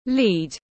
Chì tiếng anh gọi là lead, phiên âm tiếng anh đọc là /liːd/.